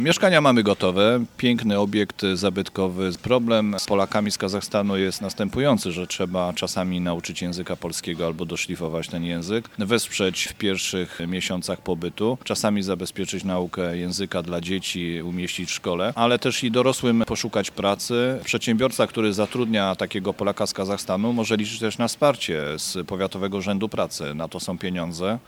Latem wszystko będzie gotowe na ich przybycie, ale oczywiście udzielimy dużo szerzej zakrojonej pomocy – mówi burmistrz Krzysztof Bagiński.